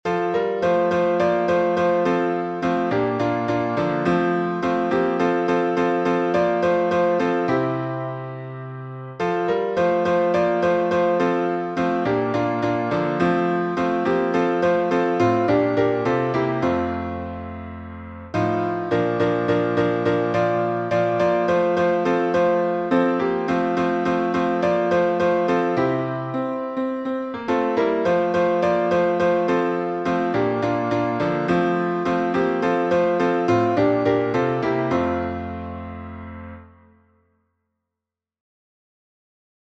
Key signature: F major (1 flat) Time signature: 4/4